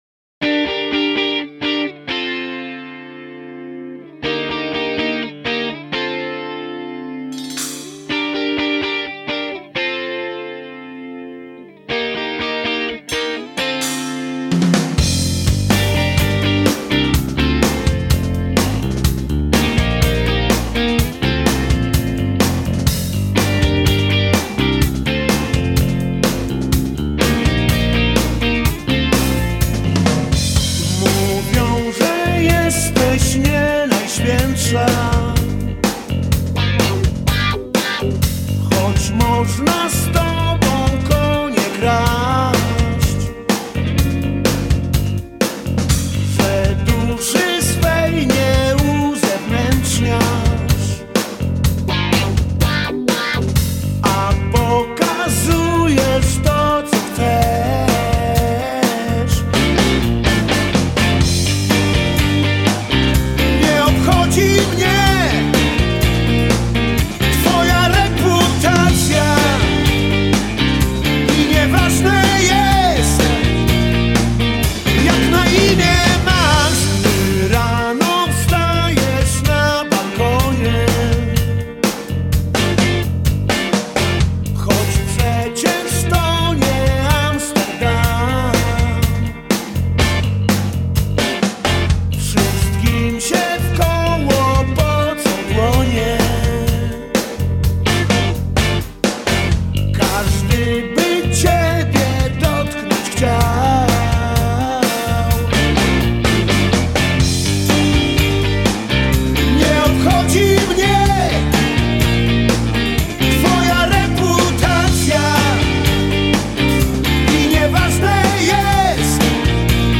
popular Polish rock band